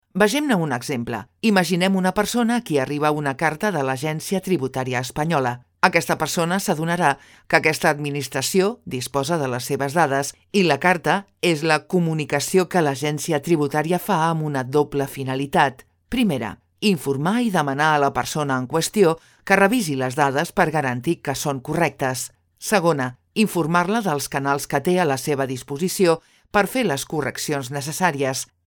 Voz clara, natural y didáctica.
E-learning